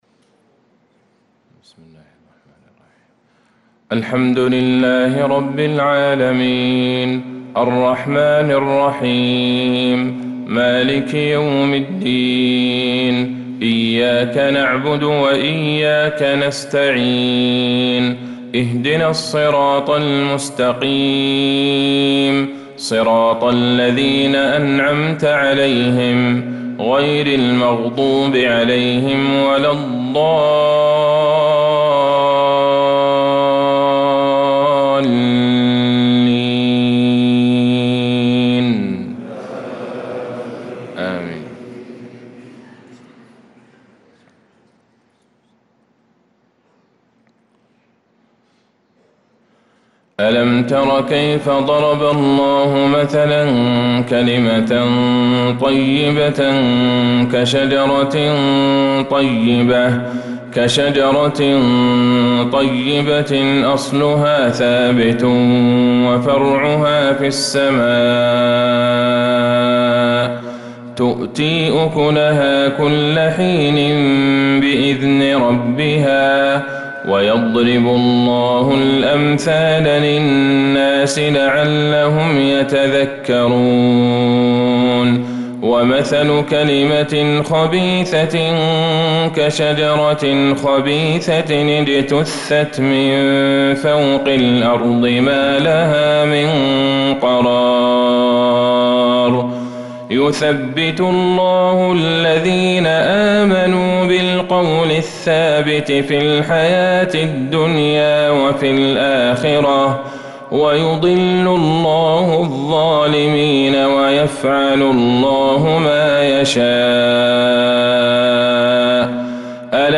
صلاة الفجر للقارئ عبدالله البعيجان 29 ذو القعدة 1445 هـ
تِلَاوَات الْحَرَمَيْن .